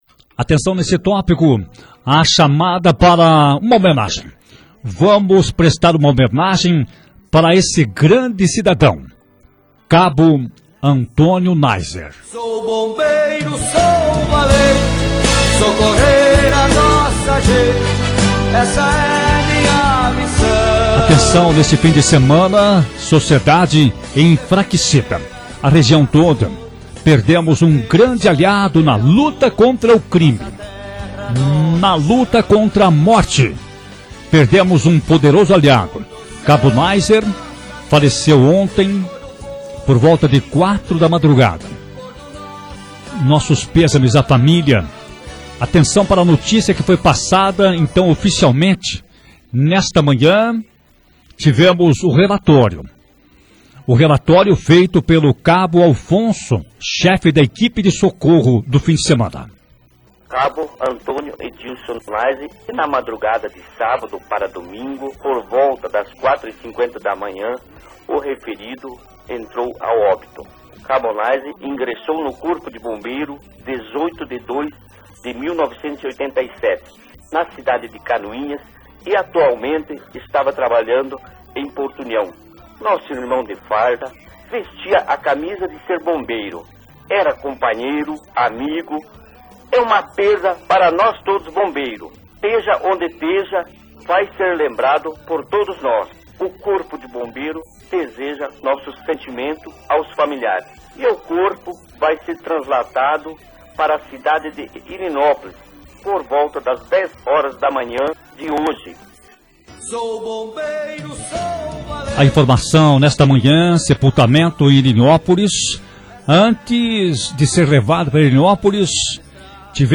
durante o Jornal Colmeia, e a homenagem do jornalismo da emissora.